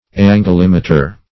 Search Result for " anglemeter" : The Collaborative International Dictionary of English v.0.48: Anglemeter \An"gle*me`ter\, n. [Angle + -meter.] An instrument to measure angles, esp. one used by geologists to measure the dip of strata.
anglemeter.mp3